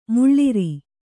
♪ muḷḷiri